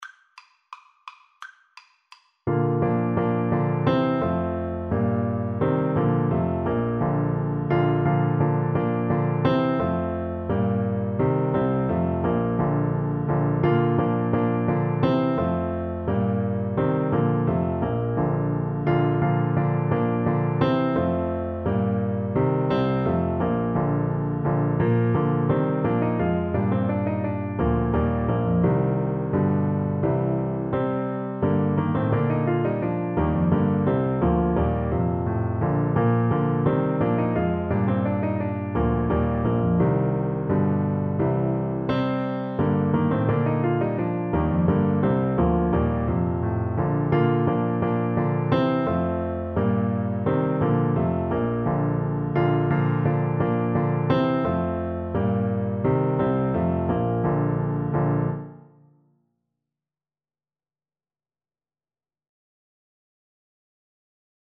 = c. 86
2/2 (View more 2/2 Music)
Classical (View more Classical Bassoon Music)